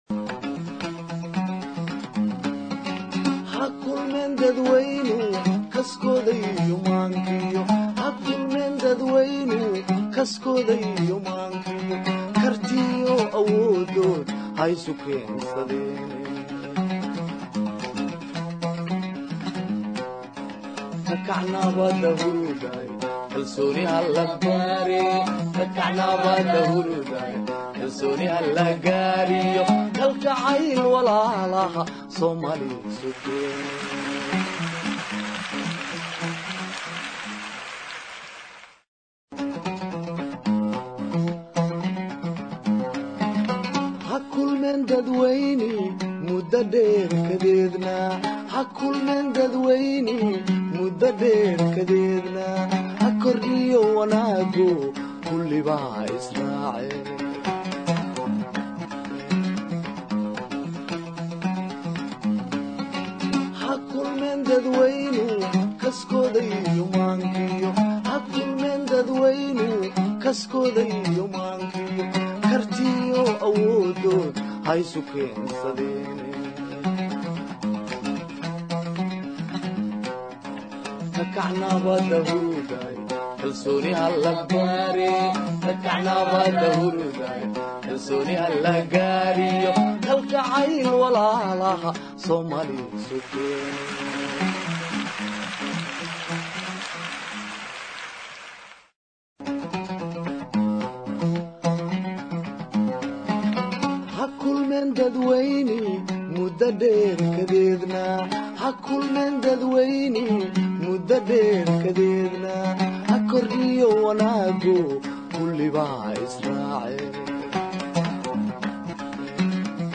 Guddiga Arrimaha bulshada Baarlamaanka Federaalka Soomaaliya oo dhageysi dadweyne mariyey Hindise-Sharciyeedka Waxbarashada dalka.